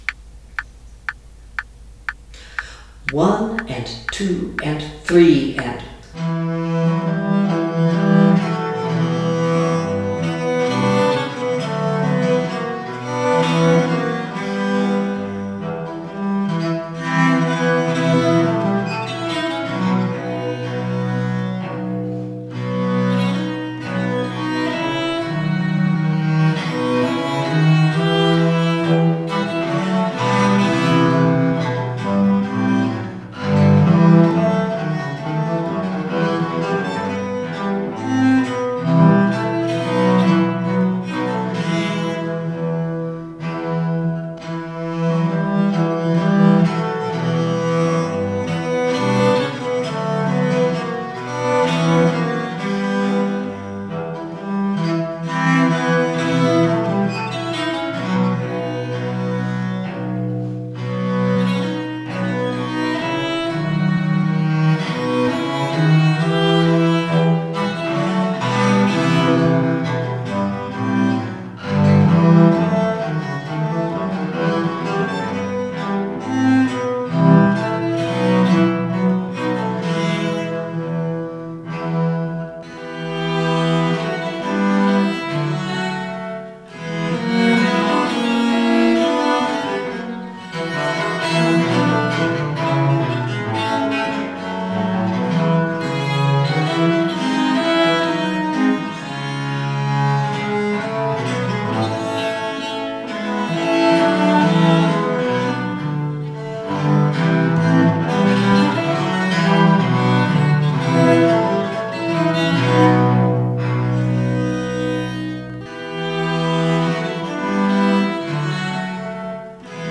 Ferrabosco galliard, mm66, both parts
Ferr-galliard-mm66.mp3